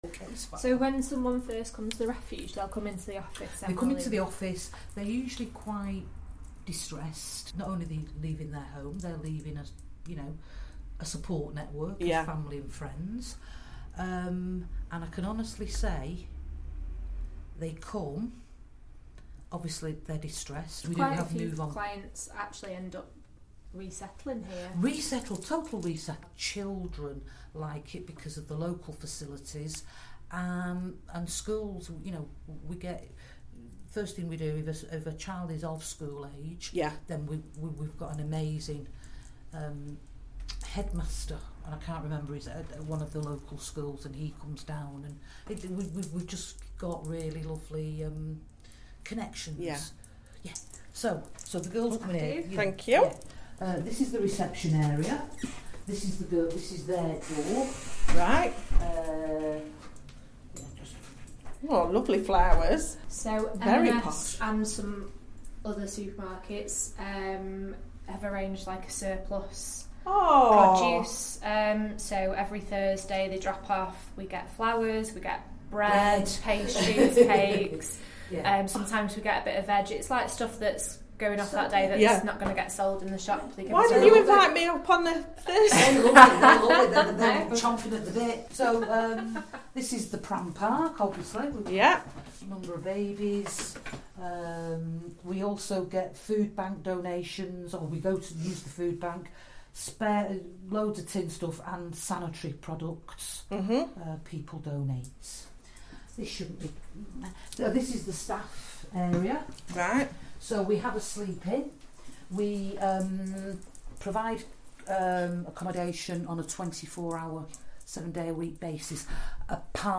Something a bit different, not a podcast, a little trip round a refuge in Derbyshire talking about the facilities available there.